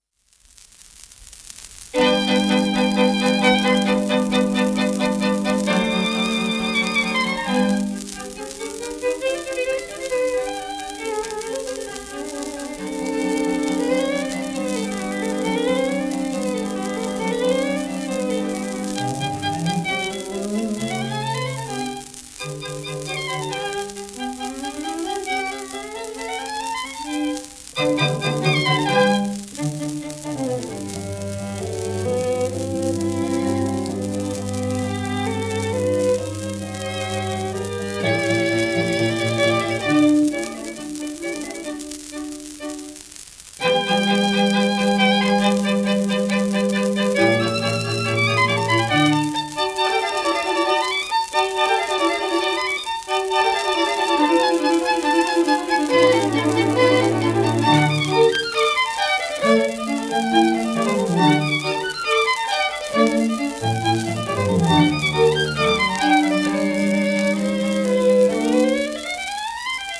ブダペスト弦楽四重奏団
budapestq_hydon_op54_1.m4a